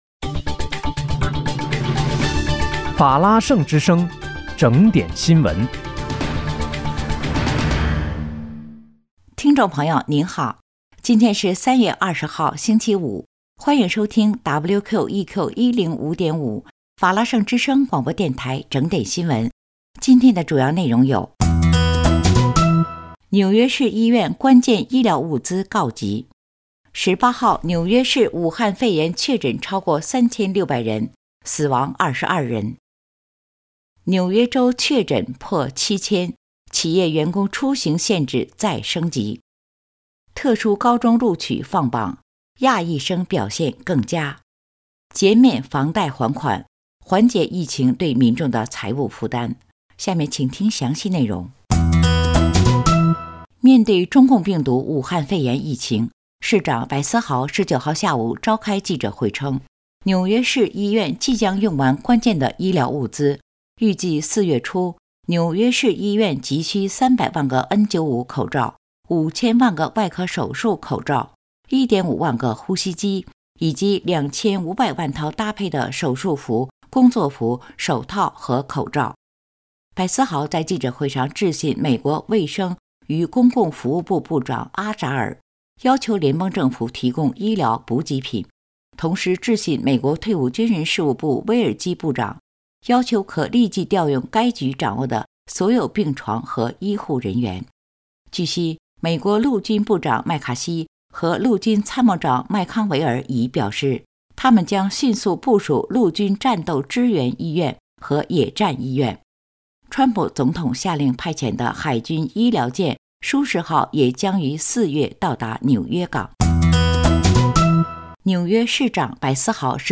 3月20日（星期五）纽约整点新闻